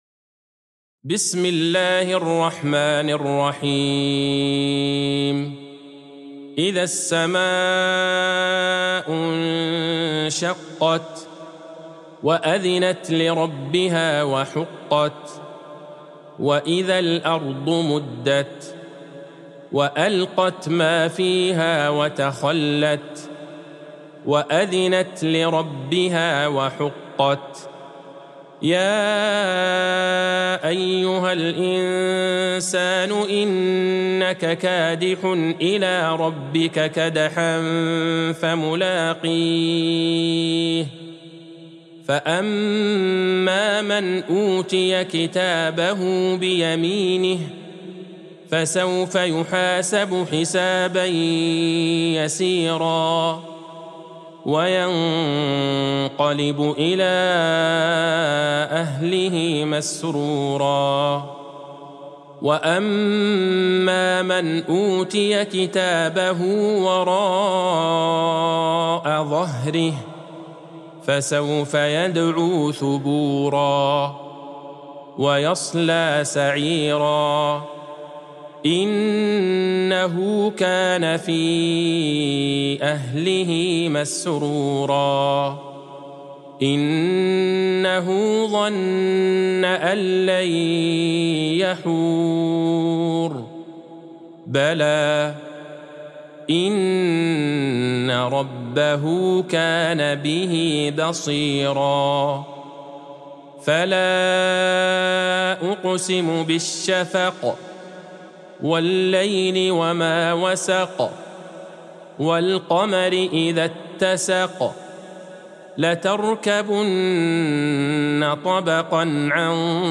سورة الإنشقاق Surat Al-Inshiqaq | مصحف المقارئ القرآنية > الختمة المرتلة ( مصحف المقارئ القرآنية) للشيخ عبدالله البعيجان > المصحف - تلاوات الحرمين